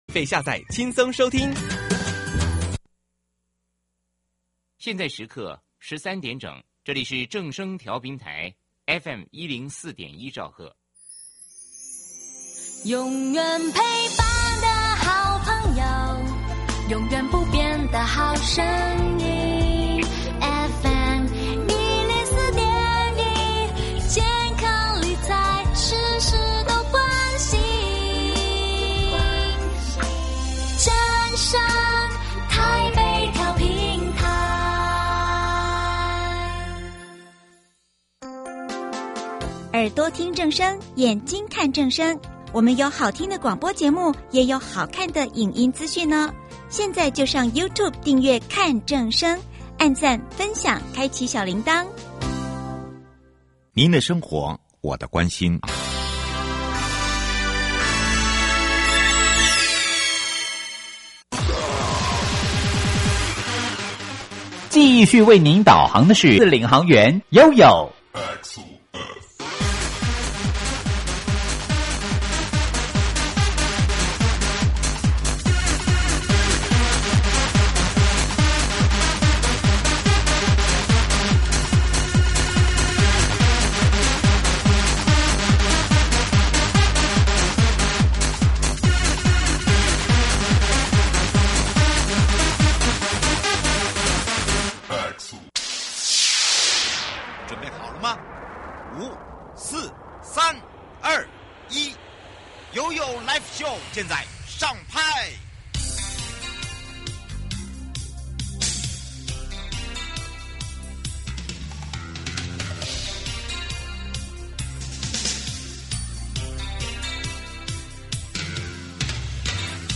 受訪者： 營建你我他 快樂平安行~七嘴八舌講清楚~樂活街道自在同行!
節目內容： 國土署 都市基礎工程組 高雄市政府交通局 劉建邦副局長(一)